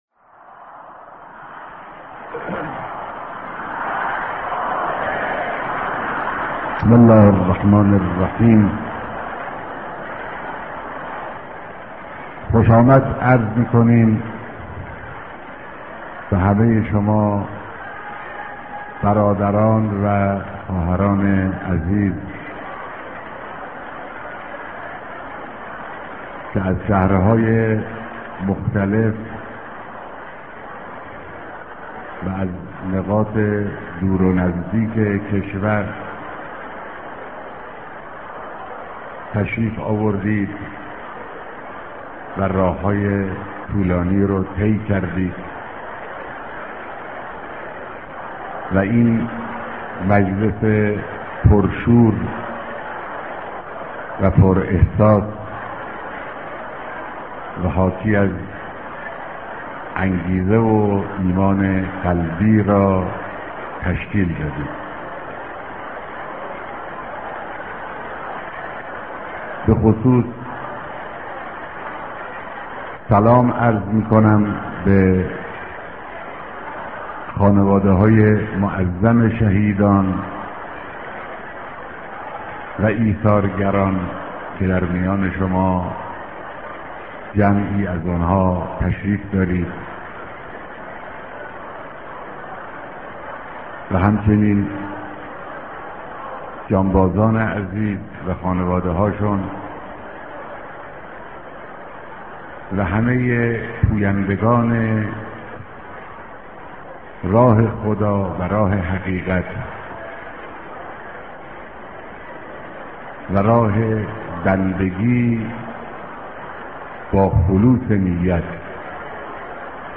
صوت کامل بیانات
دیدار جمعی از اقشار مختلف مردم با مقام معظم رهبری